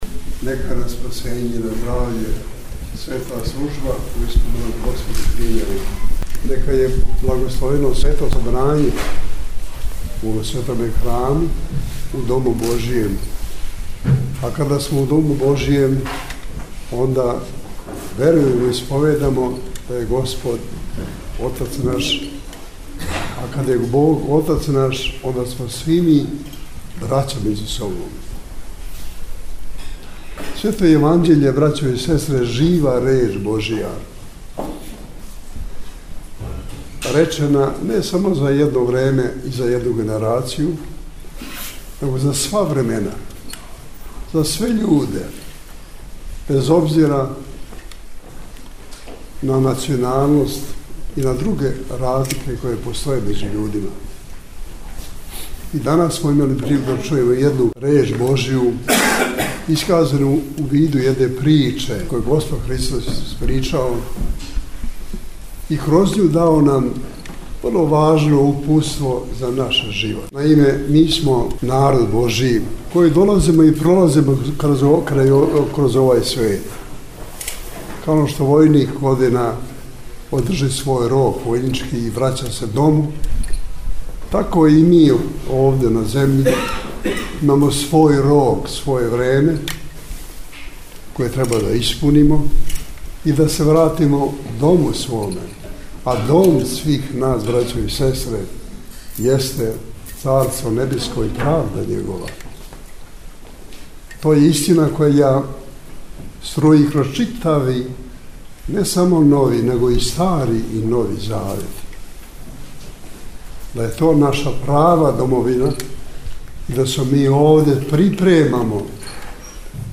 Његова Светост Патријарх српски Г. Иринеј служио је данас, 15. децембра 2019. године, у недељу 26. по Духовима, Свету Архијерејску Литургију у цркви Ружици на Калемегдану у Београду.